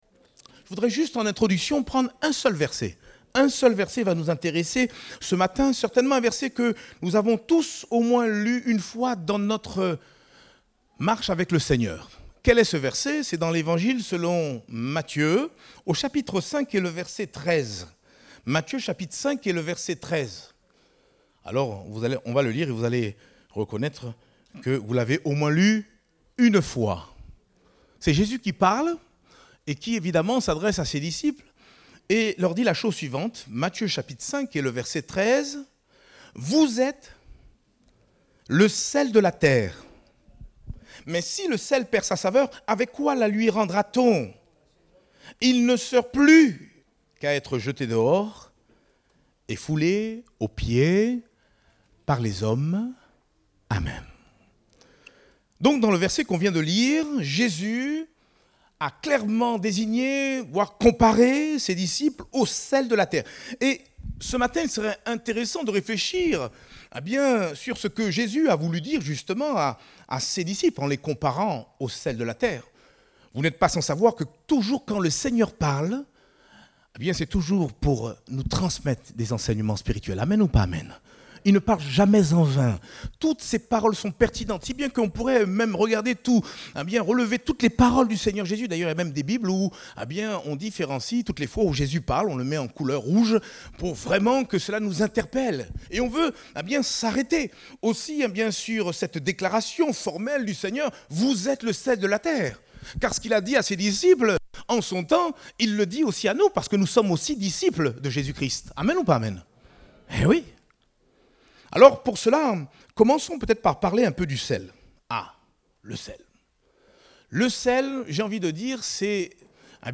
Date : 25 juin 2023 (Culte Dominical)